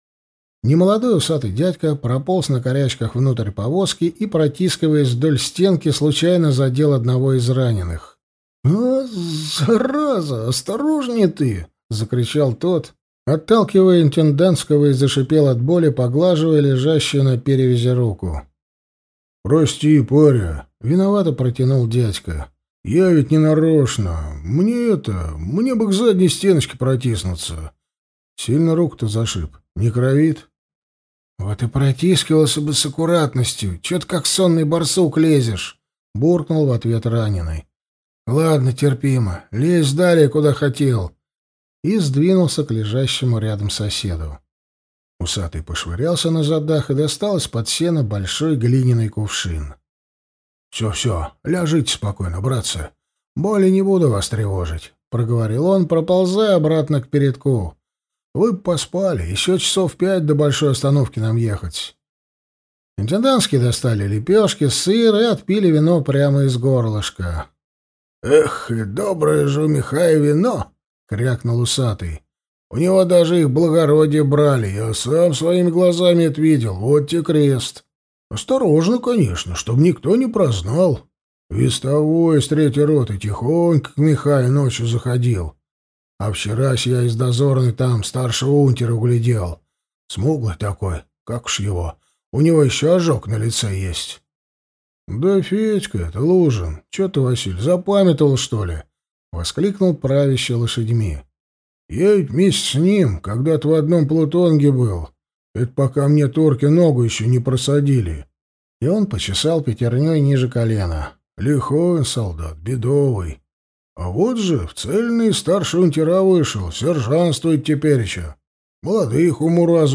Аудиокнига Егерь императрицы. Глазомер! Быстрота! Натиск! | Библиотека аудиокниг